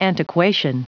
Prononciation du mot antiquation en anglais (fichier audio)
Prononciation du mot : antiquation